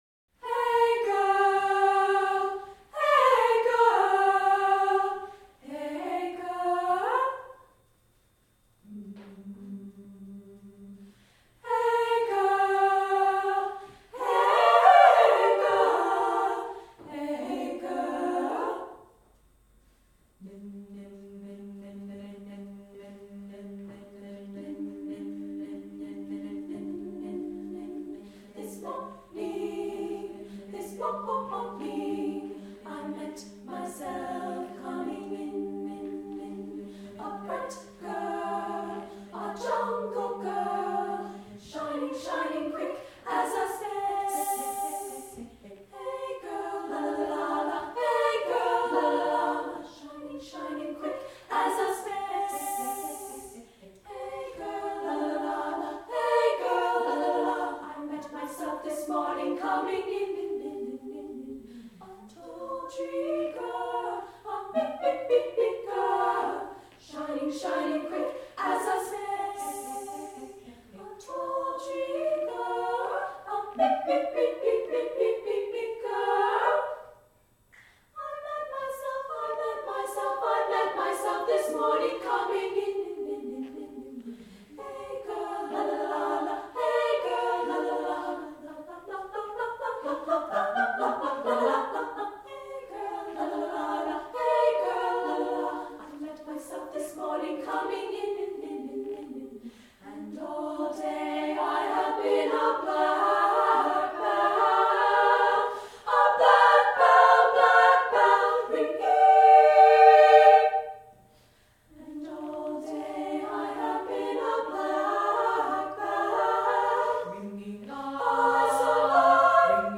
for SSA Chorus (1998)